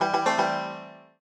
banjo_egegac1eg.ogg